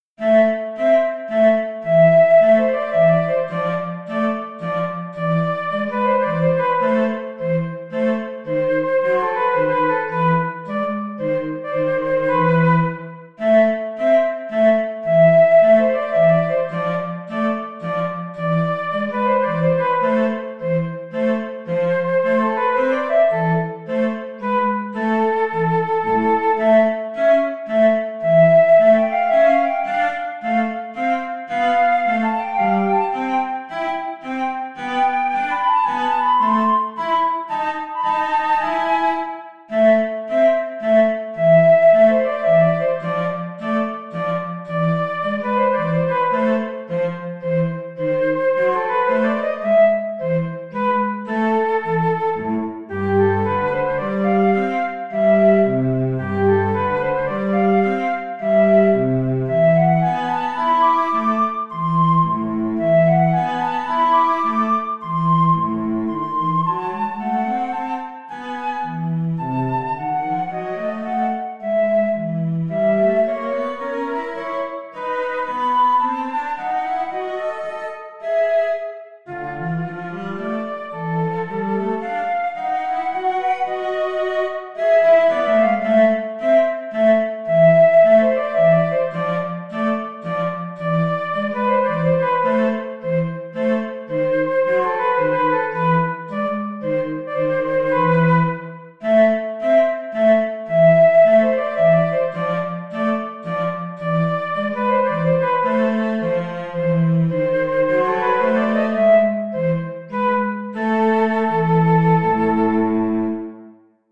All the instrumental works are recorded through Finale: notation software that gives me the sound of virtually any instrument, from simple piano to a symphony orchestra, and astonishingly lifelike.
This sonata for flute and cello has an Enlightenment feel, but doesn’t imitate any particular composer.